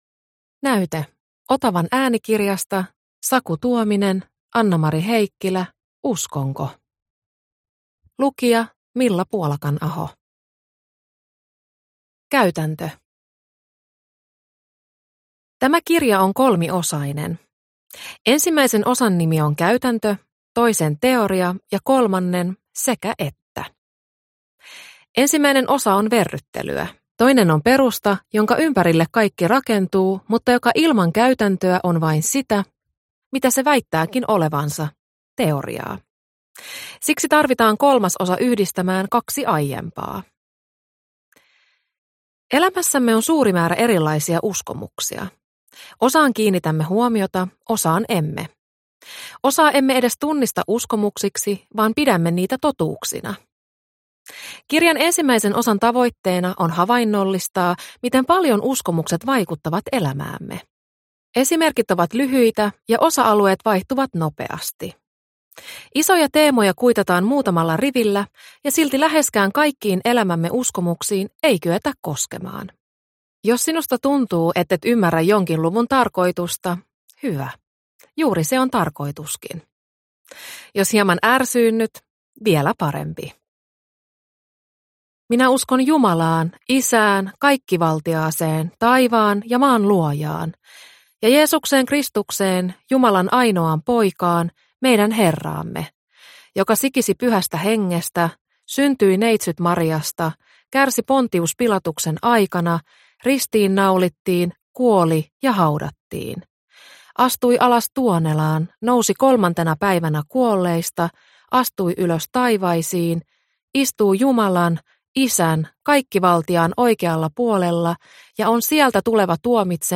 Uskonko – Ljudbok – Laddas ner